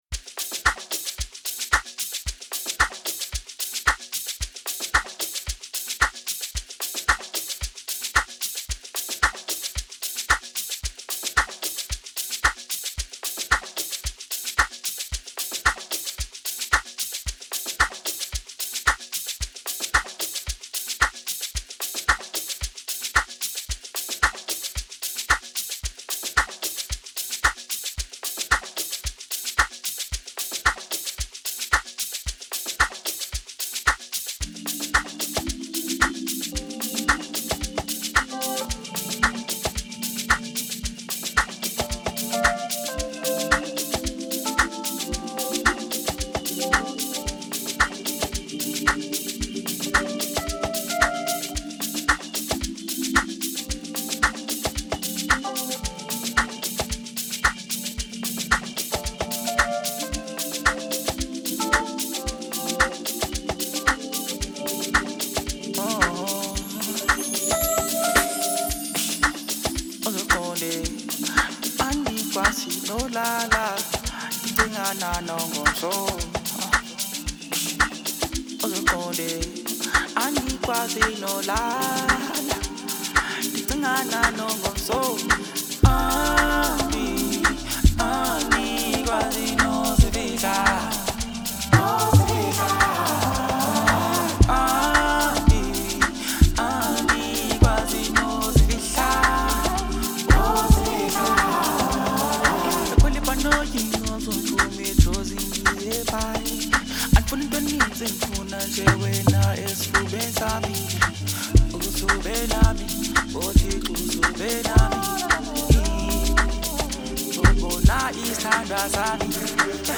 soulful and enchanting collaboration